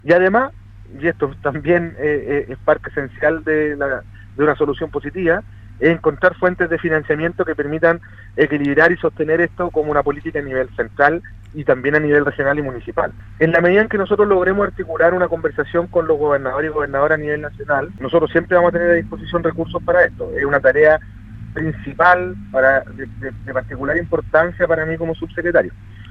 En conversación con Radio Sago, Nicolás Cataldo, Subsecretario de Desarrollo Regional y Administrativo, se refirió a los desafíos que tiene el Gobierno para manejar el problema de los residuos sólidos que enfrenta la región de Los Lagos.